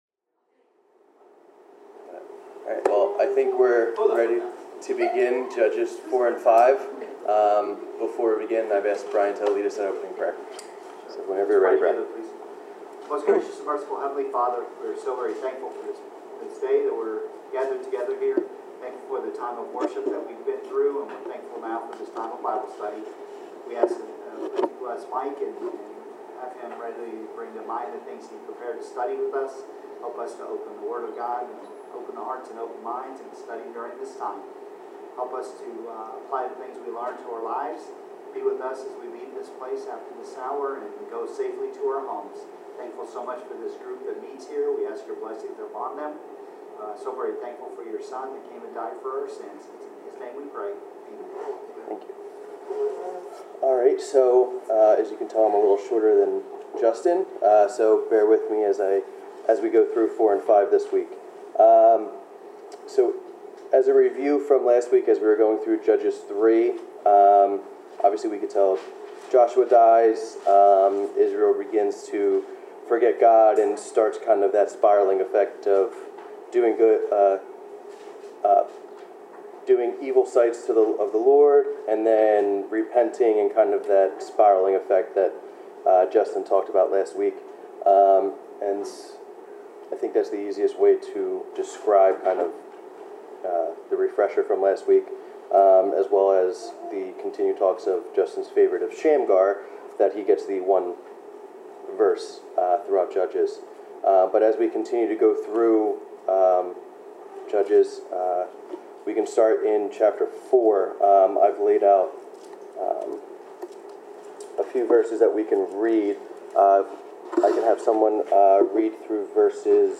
Bible class: Judges 4-5
Service Type: Bible Class